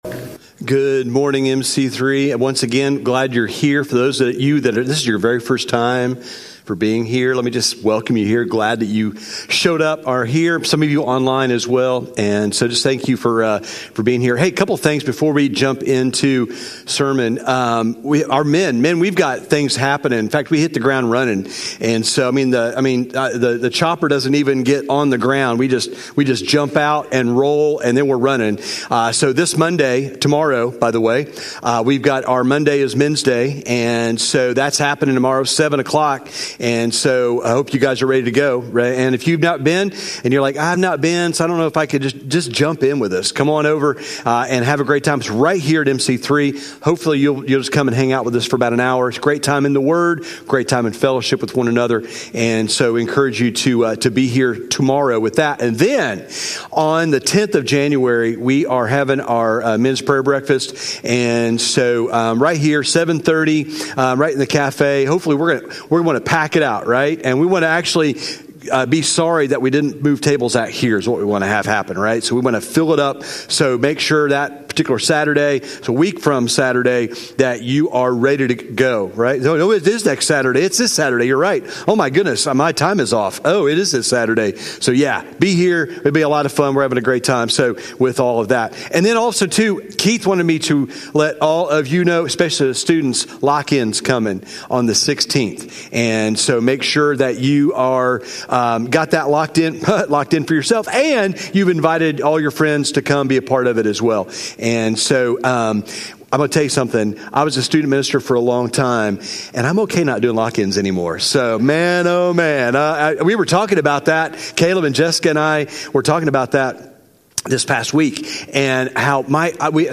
1-4-26-sermon-audio.mp3